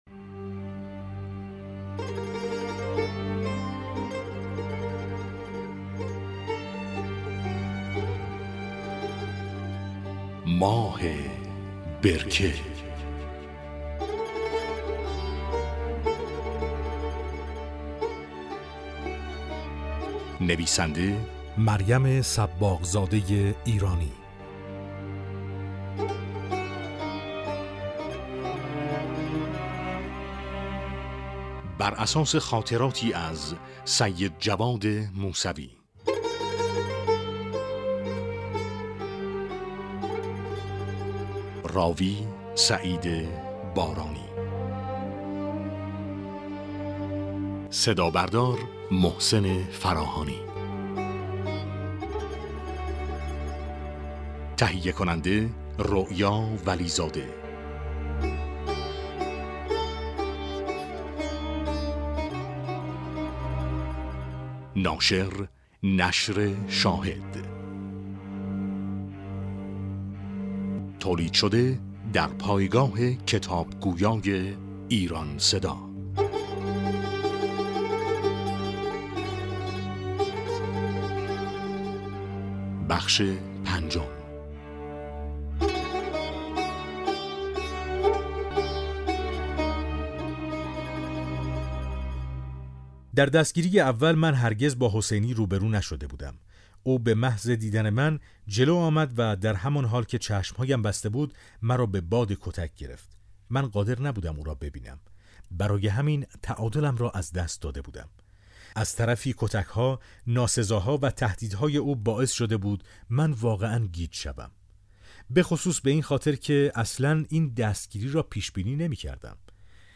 امکان دانلود رایگان کتاب صوتی «ماه برکه» فراهم شد